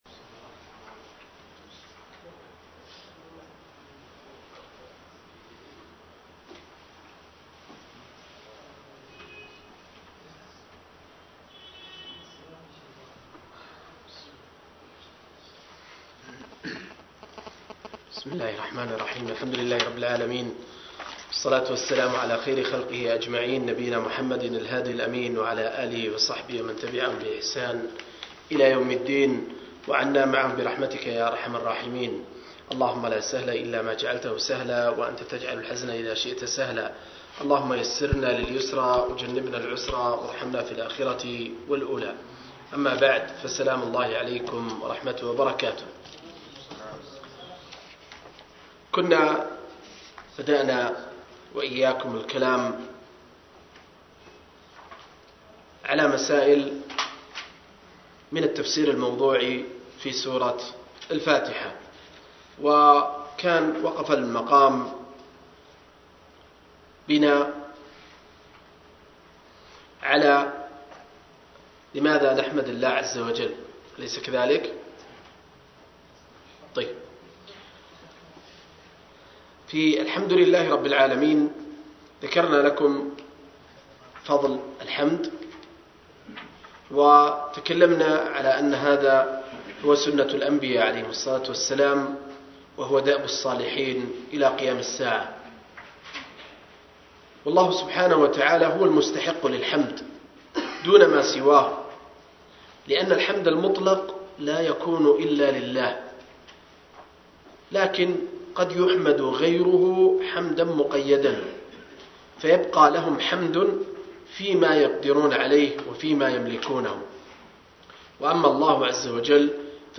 05-التفسير الموضوعي الميسر لقصار المفصل – الدرس الخامس